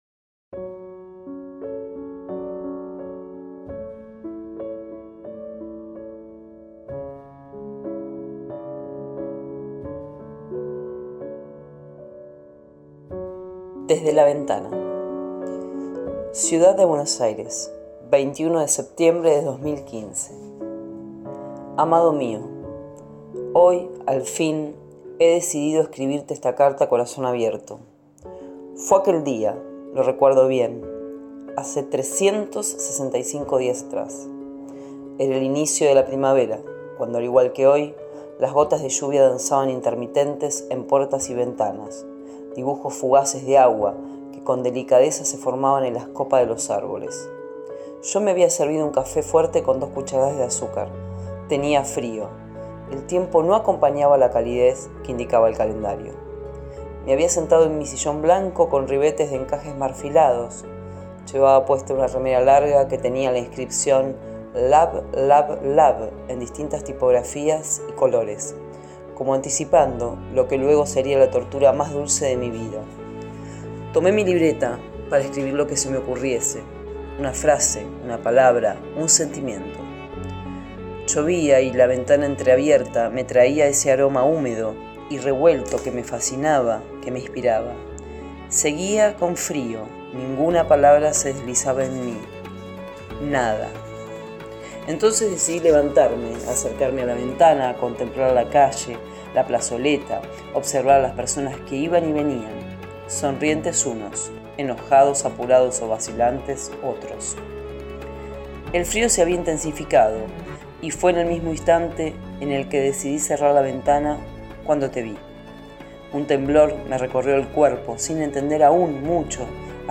Textos para escuchar